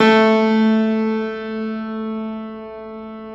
53c-pno09-A1.wav